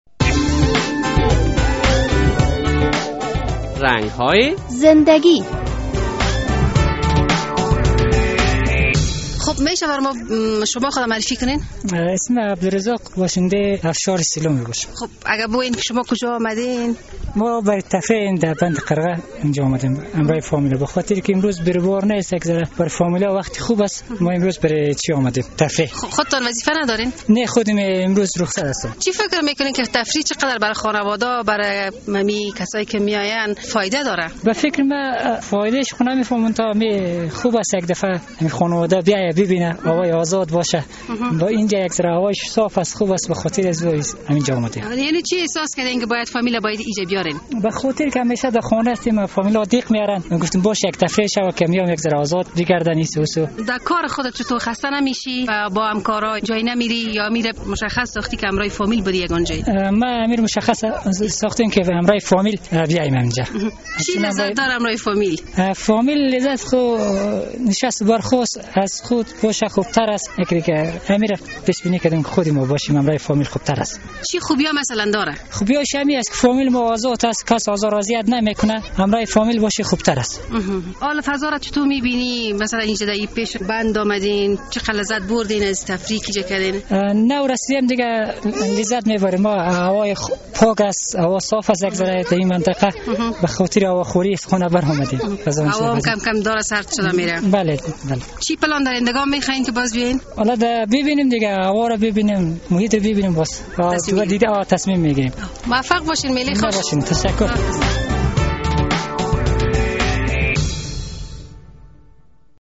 این مصاحبه کوتاه و جالب را در این برنامه رنگ های زنده گی بشنوید: